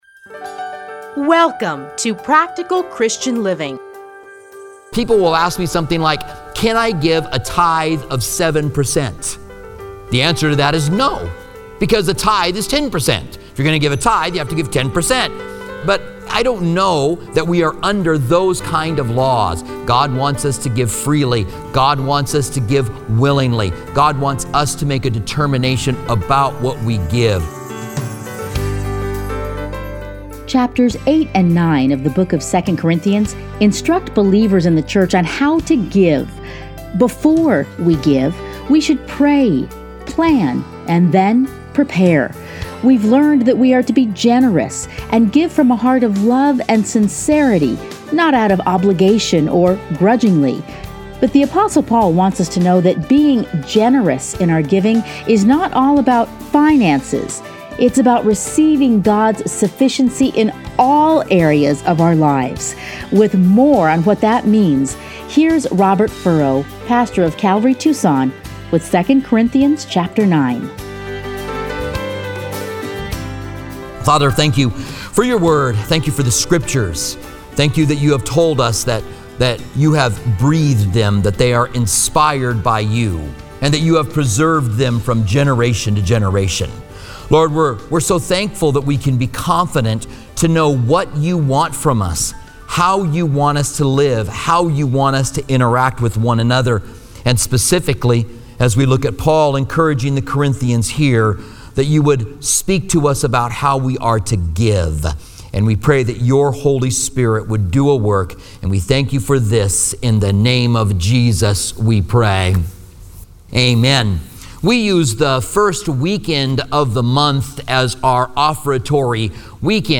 Listen here to a teaching from 2 Corinthians.